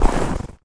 STEPS Snow, Run 29.wav